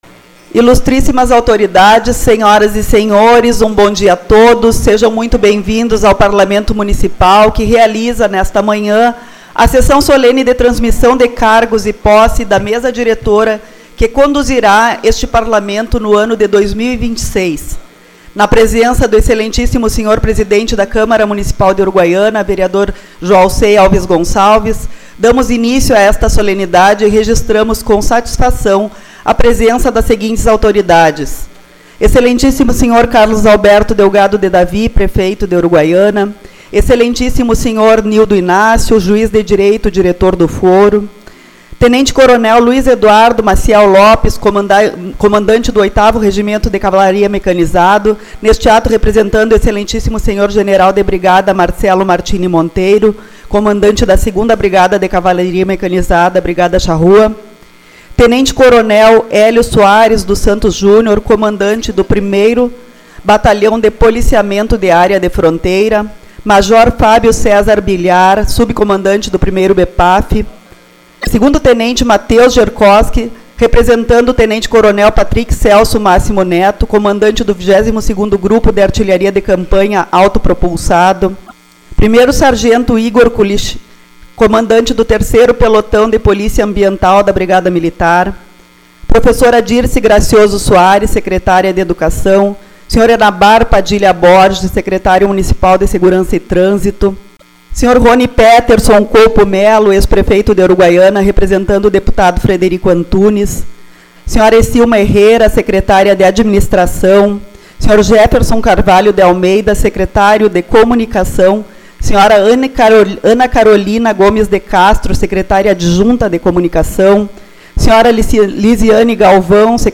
Posse da Mesa Diretora